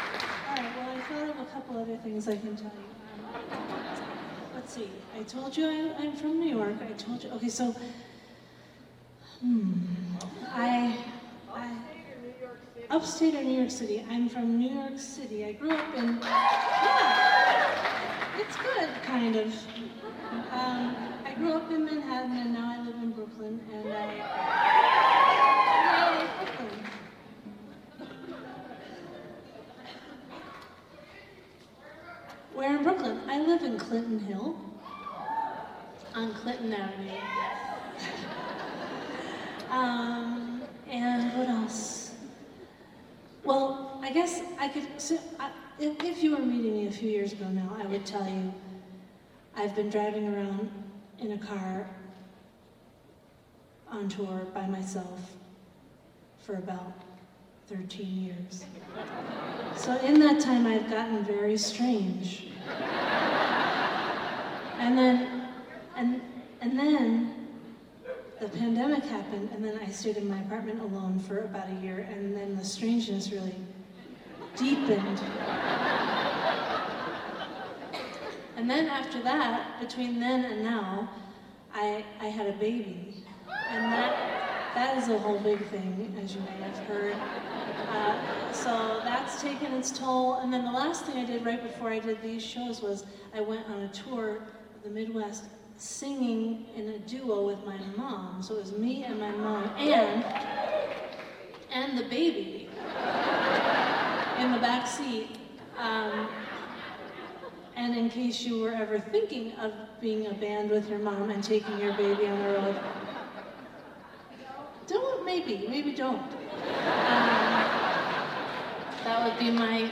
lifeblood: bootlegs: 2024: 2024-05-07: barbara b. mann performing arts hall - fort myers, florida